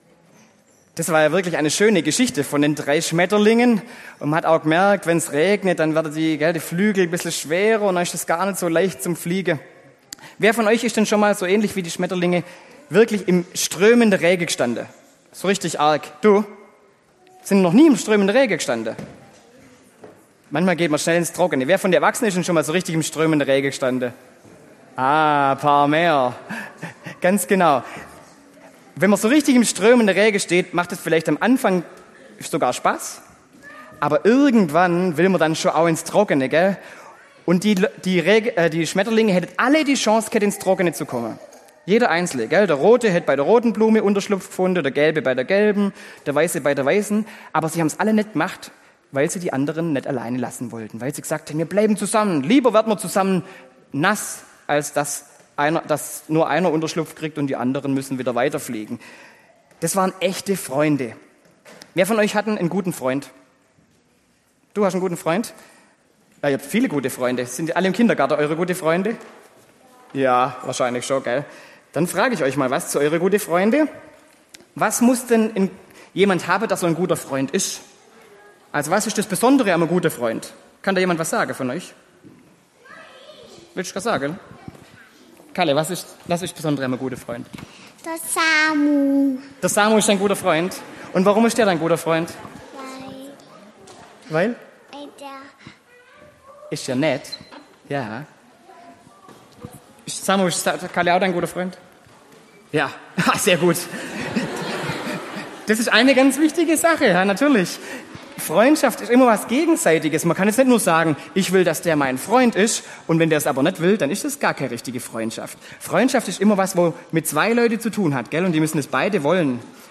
Predigt zum Kindergartenfest: Freundschaft (Joh 15, 13-15) – Kirchengemeinde Bernloch, Meidelstetten mit Oberstetten